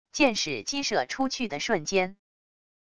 箭矢激射出去的瞬间wav音频